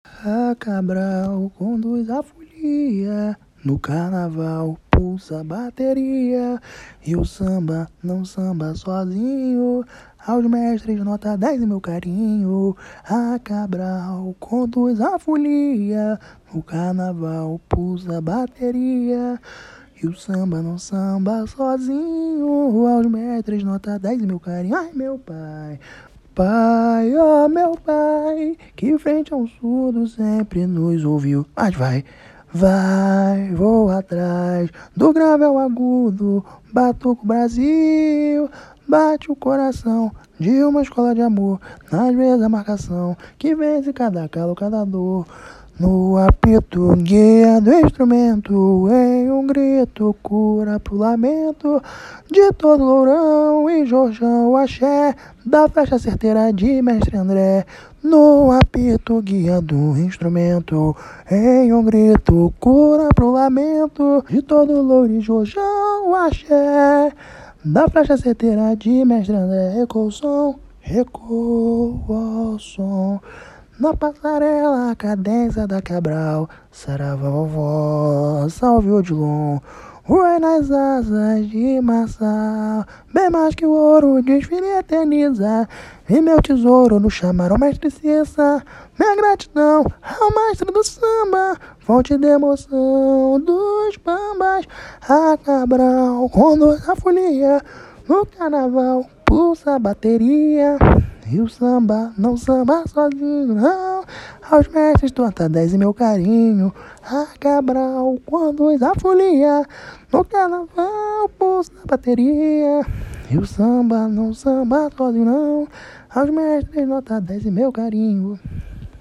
Samba  01